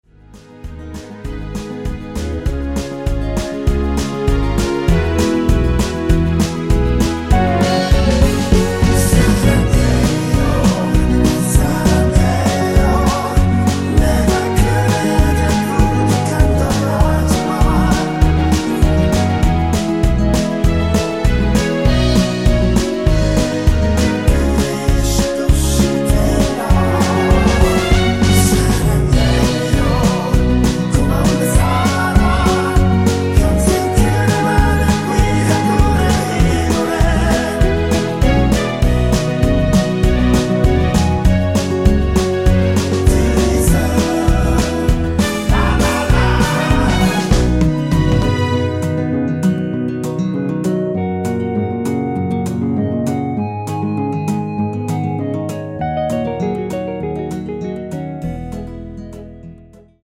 전주가 길어서 8마디로 편곡 하였으며
원키에서(-1)내린 (1절+후렴)으로 진행되는 멜로디와 코러스 포함된 MR입니다.
앞부분30초, 뒷부분30초씩 편집해서 올려 드리고 있습니다.
중간에 음이 끈어지고 다시 나오는 이유는